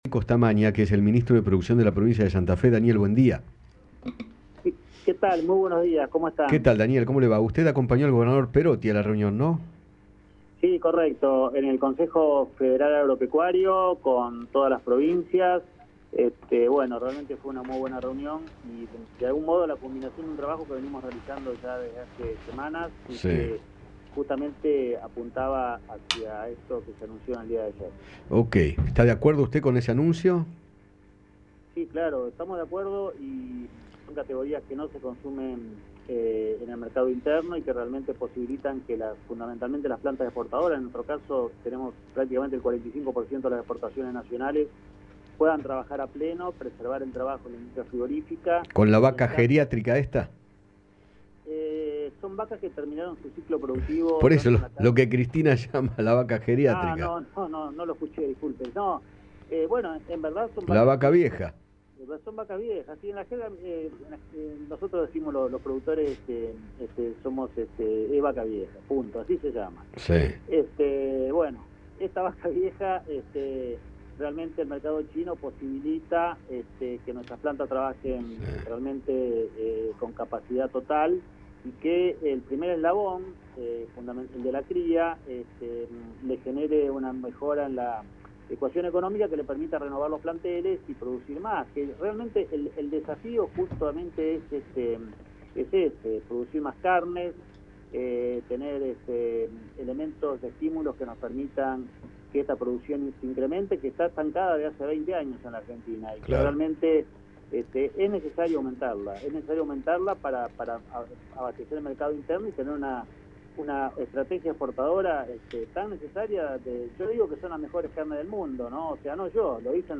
Daniel Costamagna, ministro de Producción de Santa Fe, habló con Eduardo Feinmann sobre la medida que tomó el Gobierno y sostuvo que “el desafío es producir más carne, porque está estancada hace 20 años en la Argentina”.